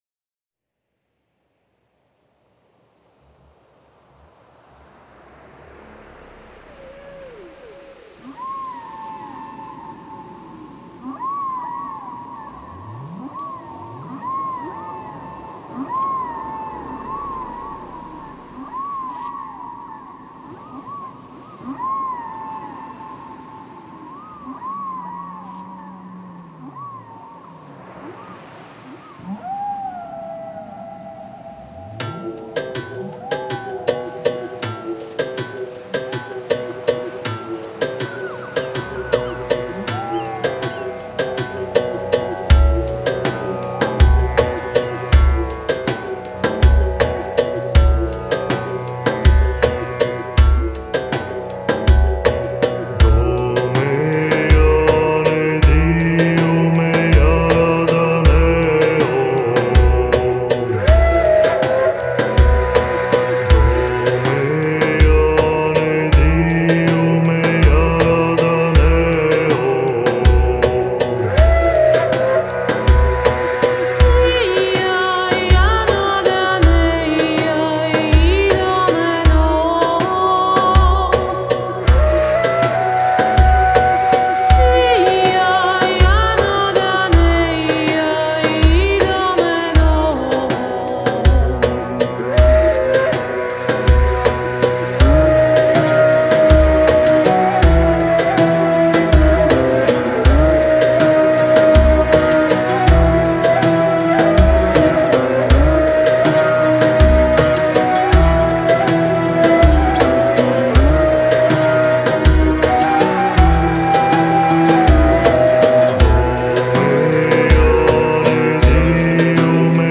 موسیقی ملل